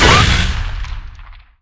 HitEnergy.wav